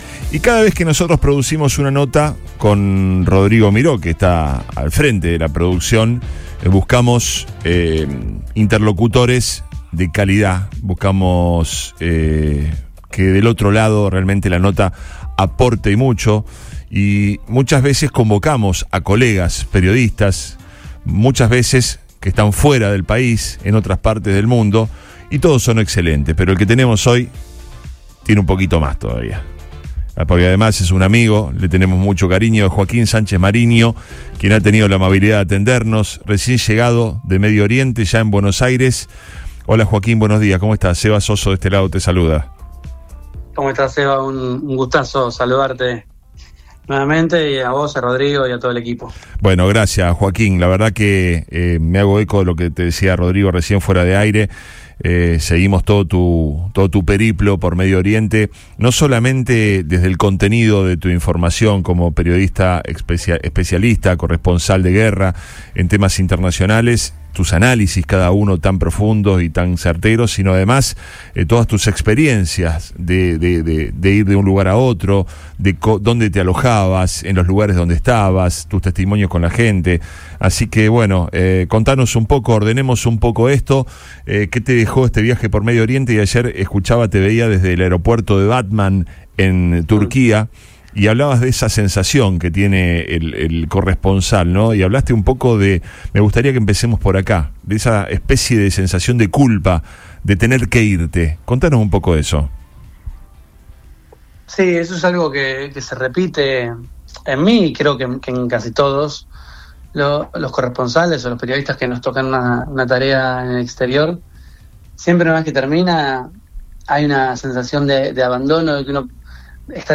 dialogó con el equipo de Radio Río sobre la intensidad de la guerra en la región y el impacto emocional que conlleva su profesión.